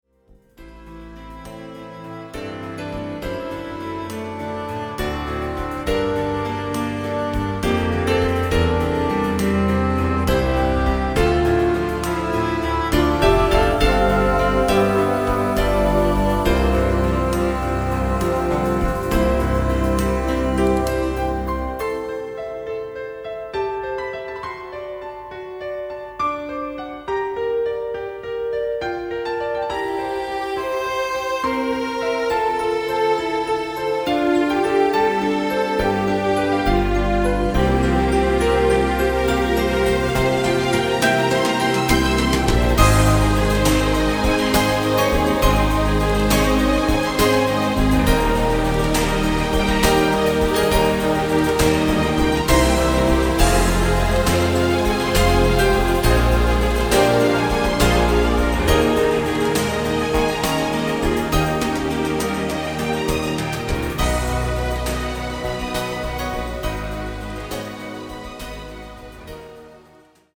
Slow Waltz